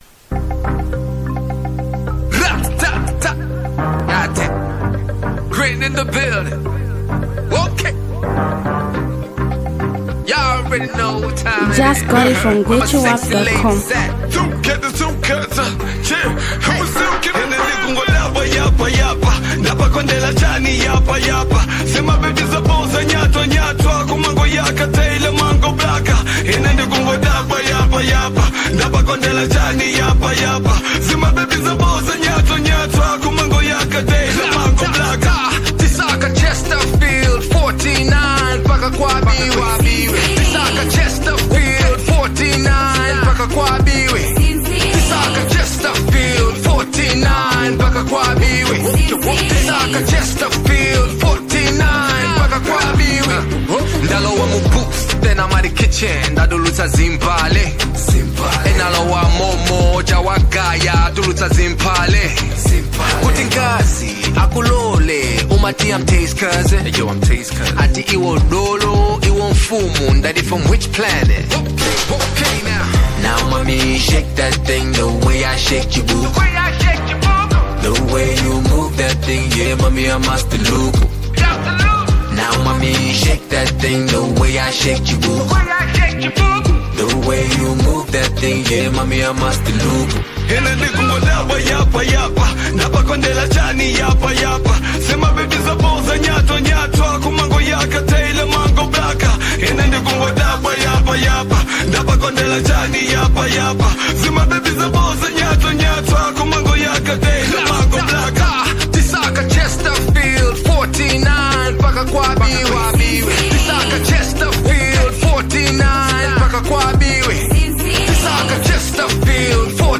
a renowned rap and songwriter